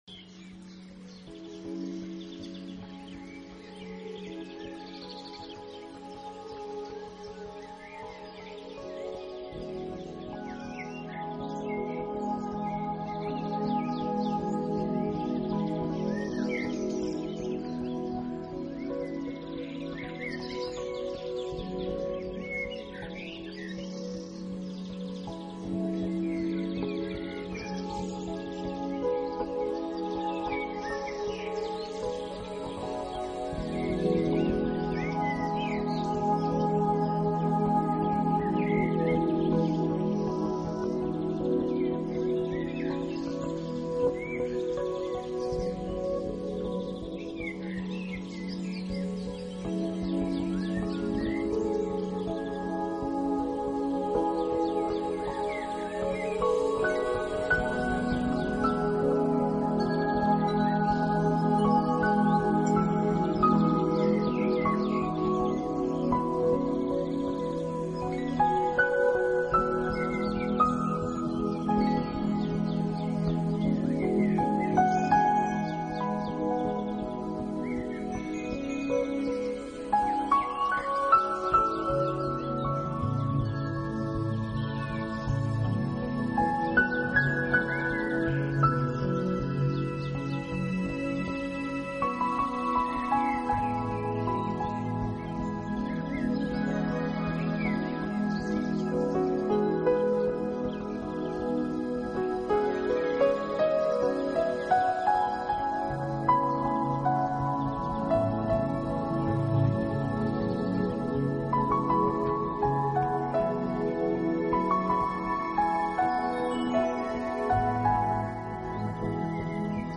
唱片类型：New  Age